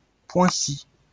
Poincy (French: [pwɛ̃si]